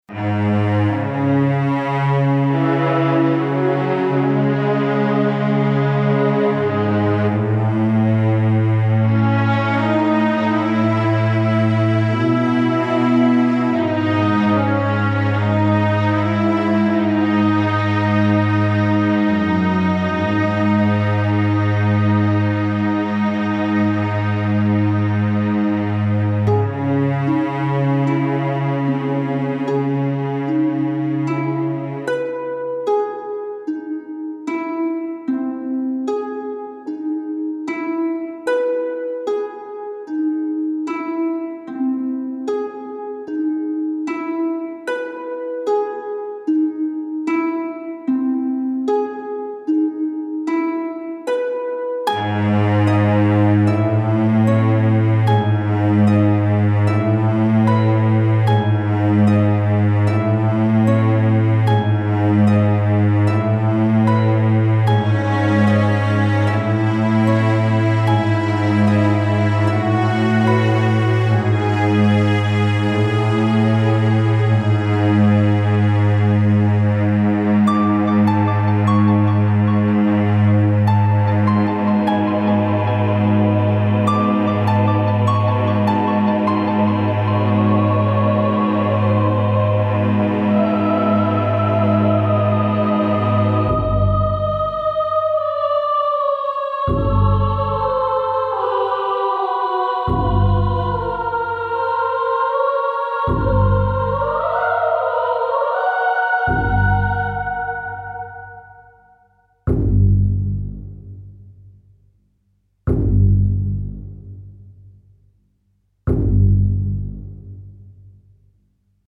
Incidental Music Samples